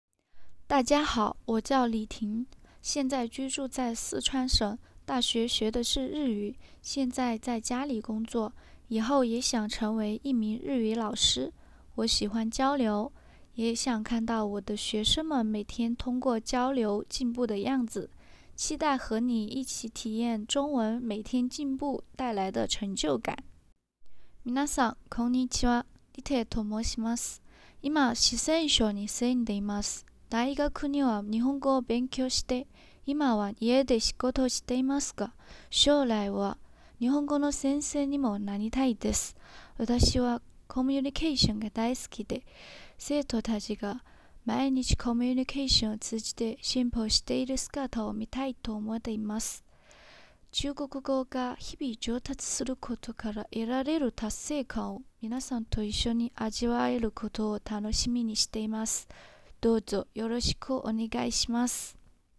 自己紹介：
きれいな日本語を話される先生です。はっきりとした分かりやすい説明が持ち味です。